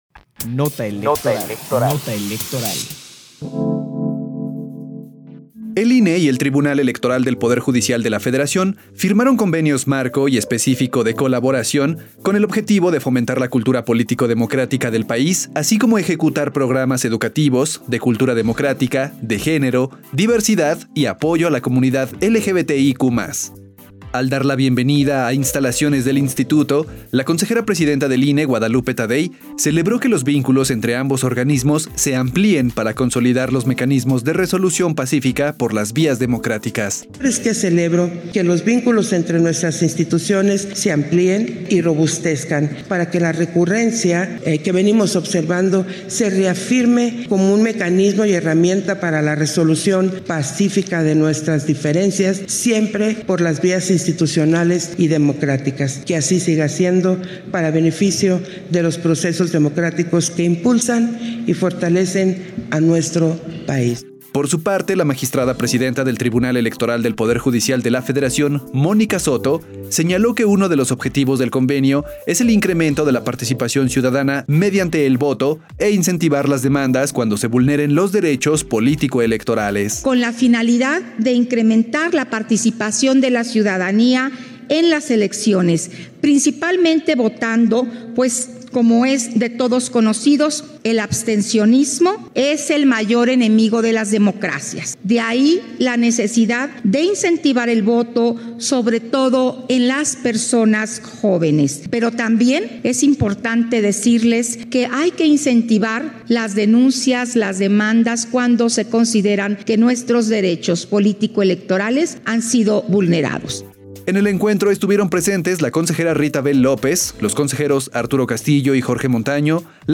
Nota de audio sobre la firma de convenio entre el INE y el Tribunal Electoral del Poder Judicial de la Federación, 11 de marzo de 2024